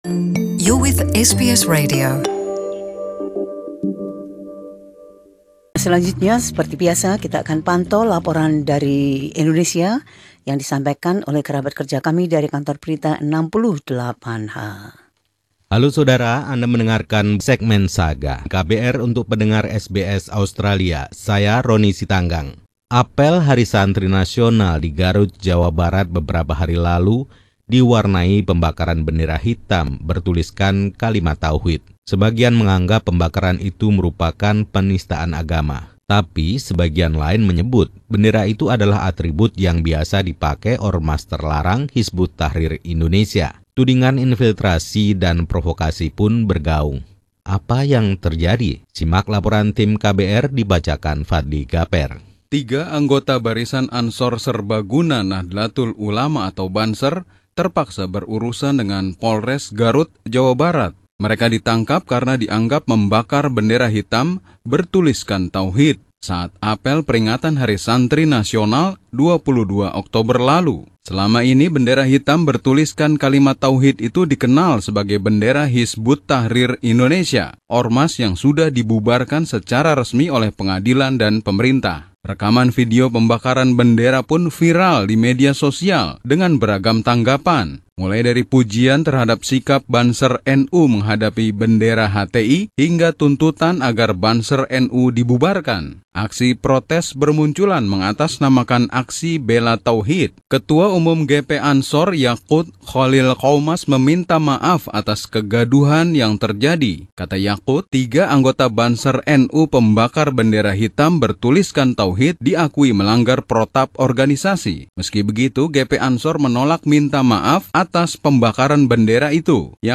Mengapa melakukan investigasi dan penuntutan dalam hal ini rumit? Laporan khusus tim KBR 68H ini menguraikan isu-isu yang ada.